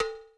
RX5 COWBELL.wav